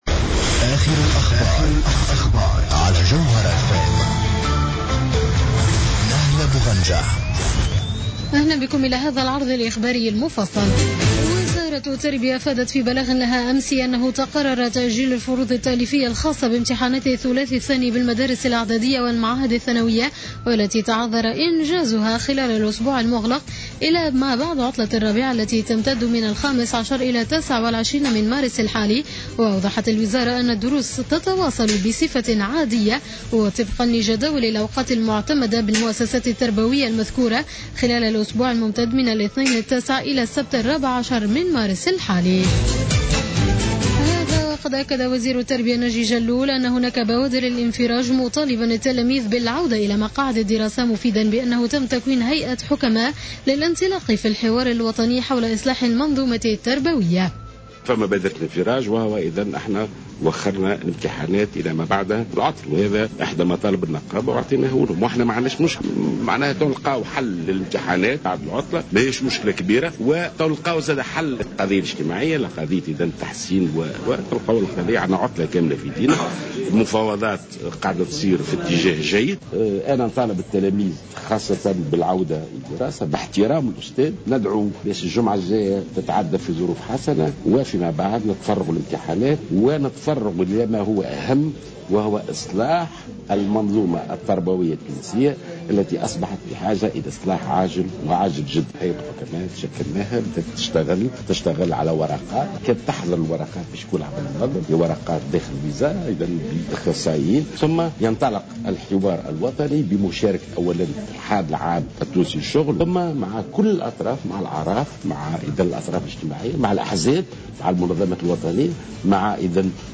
نشرة الأخبار منتصف الليل ليوم السبت 07 مارس 2015